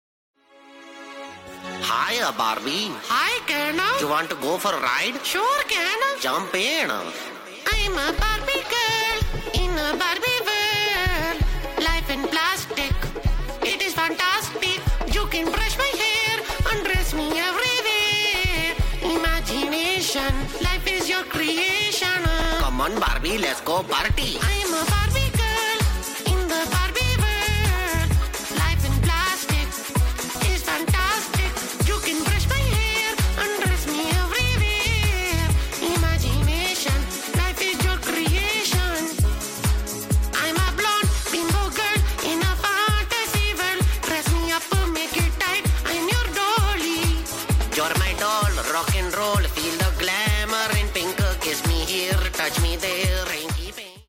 Indian version